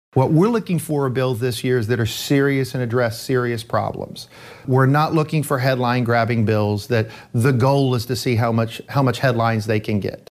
CLICK HERE to listen to commentary from state Representative Jon Echols.